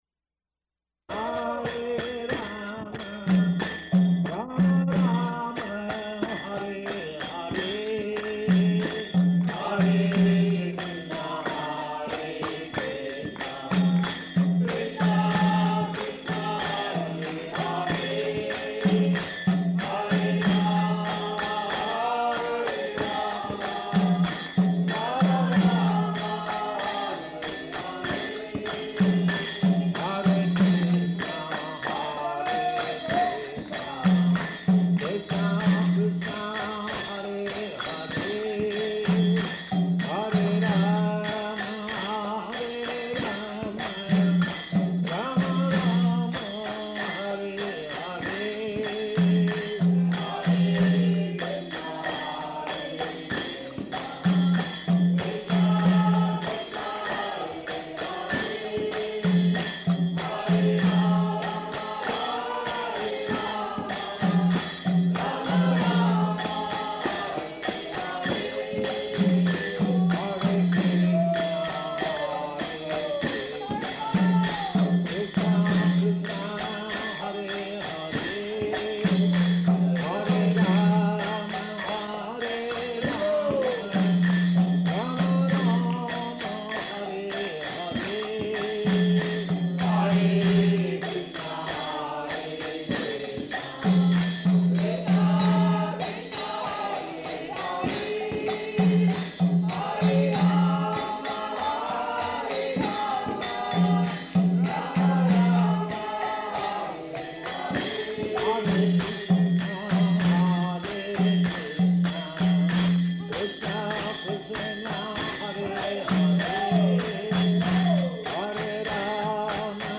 Lecture at Harvard University Divinity School, Cambridge
Type: Lectures and Addresses